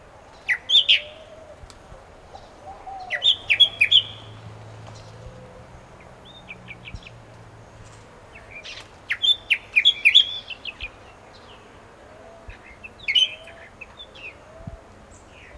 Tropical Mockingbird
song often wakes you in the morning, as they typically begin singing before first light.
TropicalMockingbirdArubaJan95.aif